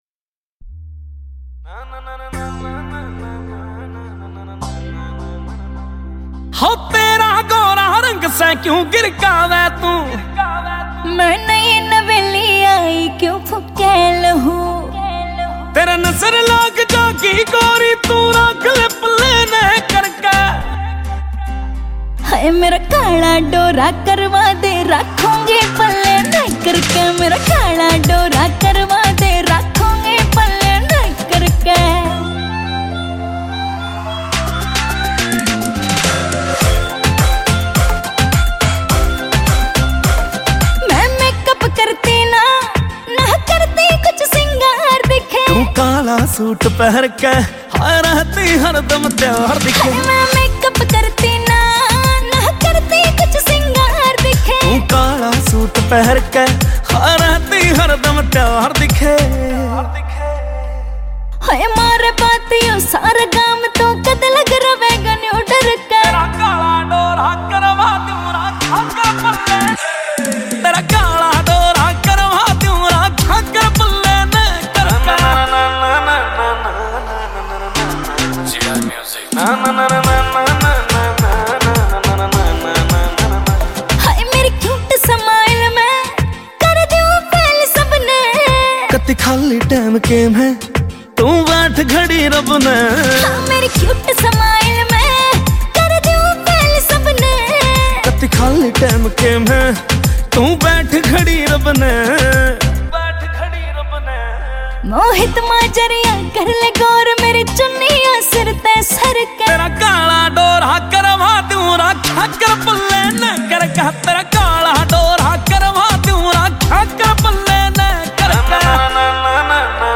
Haryanvi Mp3 Songs